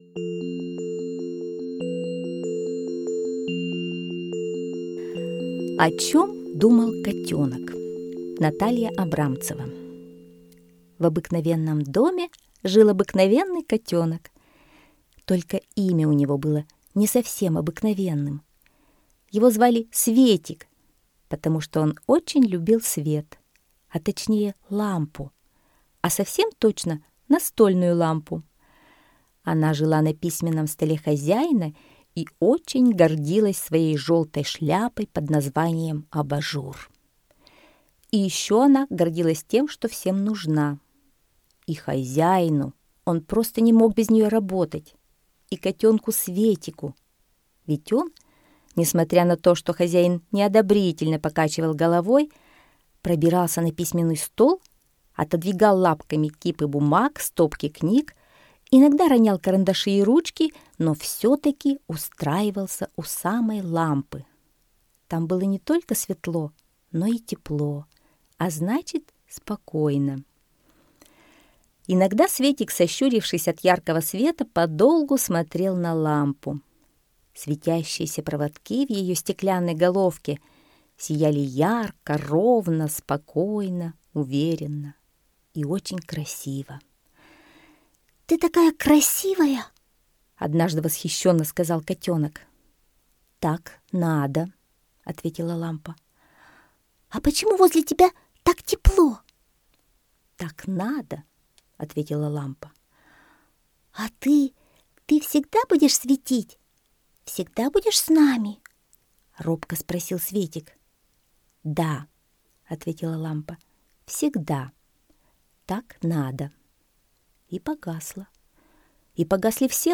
Слушайте О чем думал котенок - аудиосказка Абрамцевой Н. Сказка про котенка, который очень любил лежать под настольной лампой с желтым абажуром.